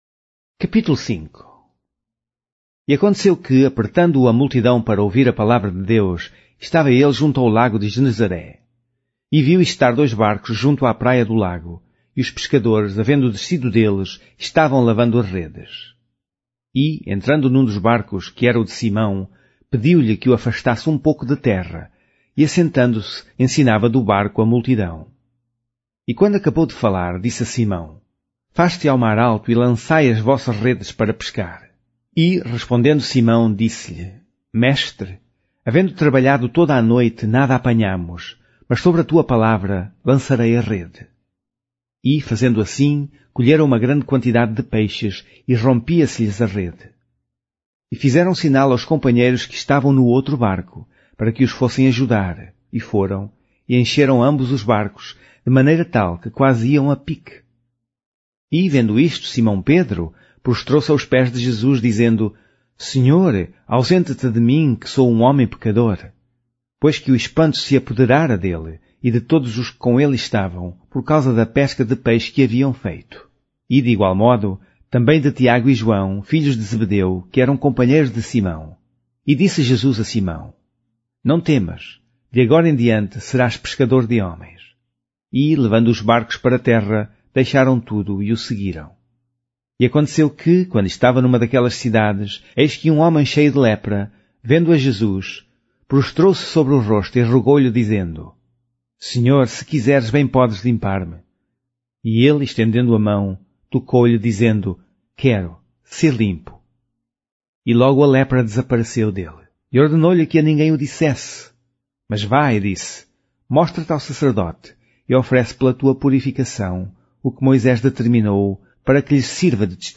Wordplanet: Narração em áudio: A pesca maravilhosa, os primeiros discípulos.